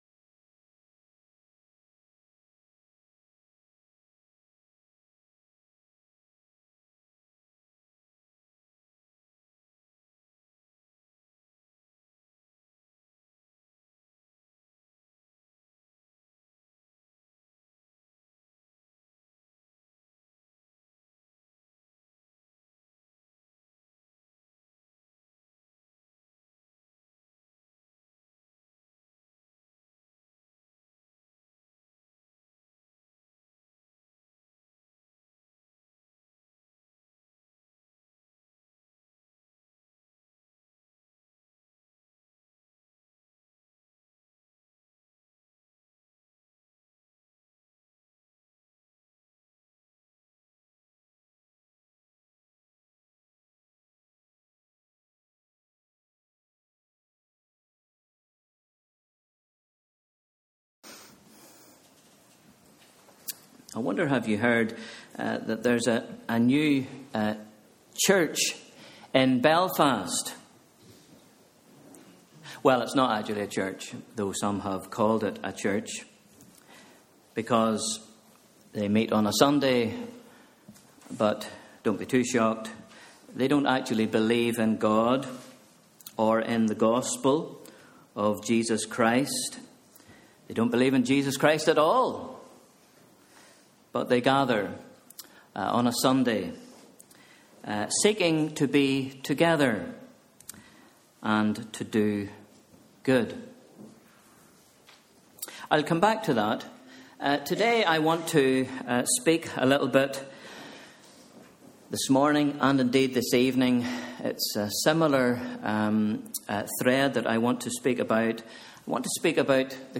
Sunday 8th December 2013 – Morning Service